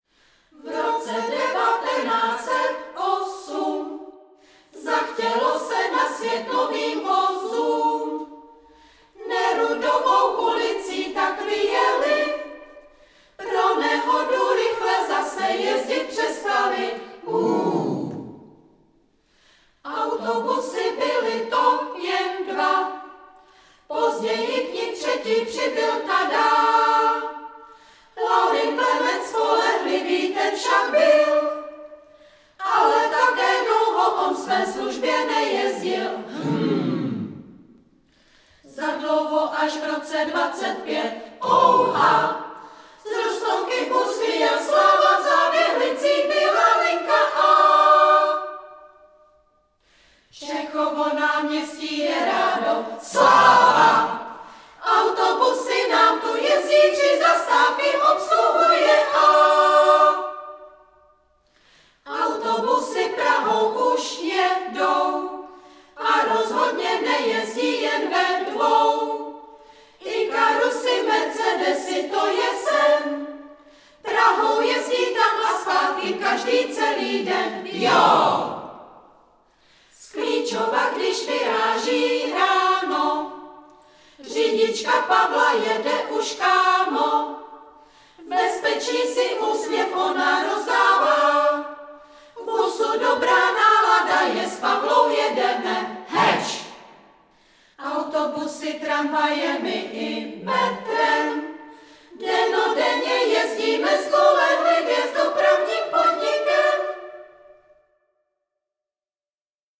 Pěvecký sbor DPP | Pěvecký sbor